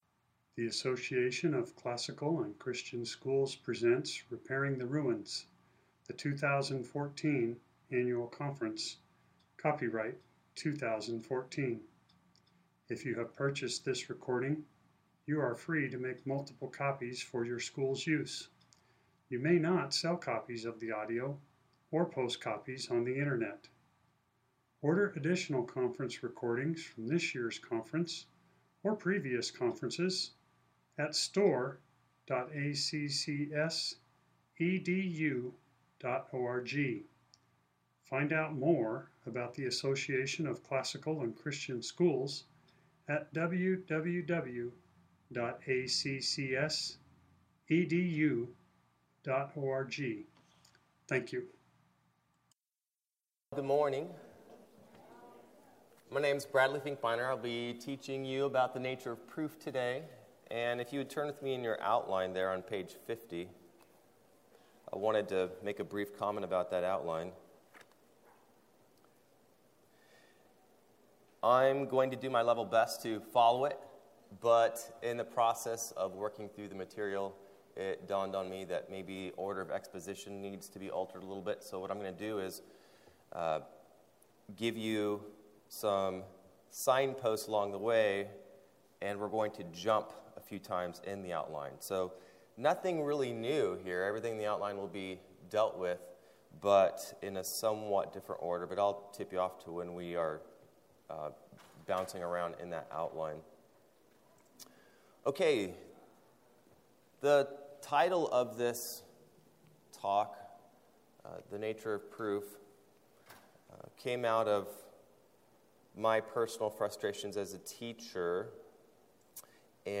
2014 Workshop Talk | 1:06:28 | All Grade Levels, Logic
The Association of Classical & Christian Schools presents Repairing the Ruins, the ACCS annual conference, copyright ACCS.